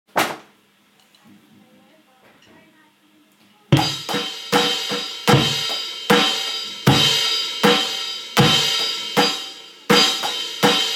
basic drum